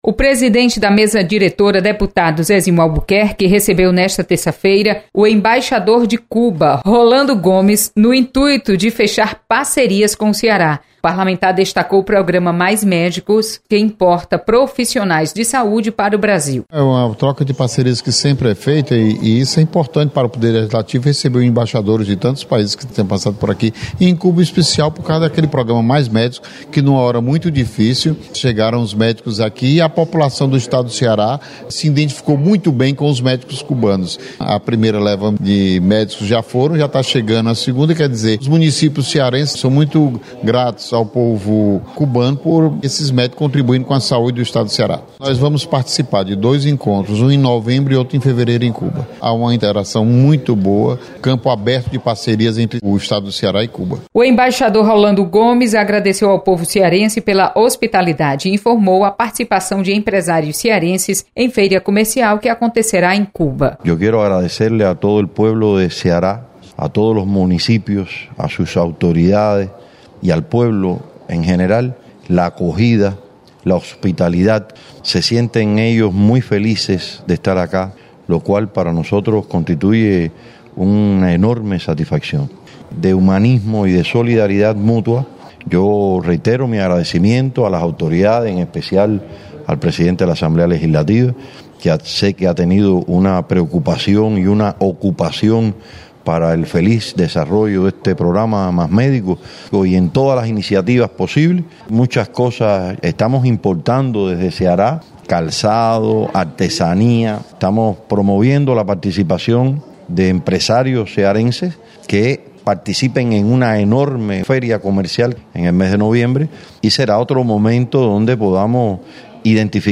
Assembleia Legislativa recebe do embaixador de Cuba nesta terça-feira. Repórter